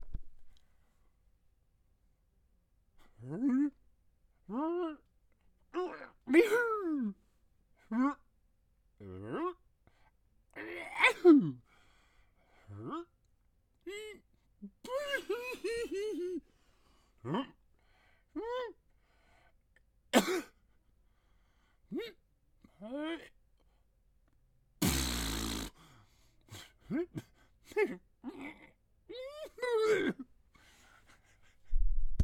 Cartoon_Dragon_Spits_01
cartoon choke cough exhale funny goofy ill male sound effect free sound royalty free Memes